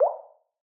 message-notif.02a789f0ec9ee21d.mp3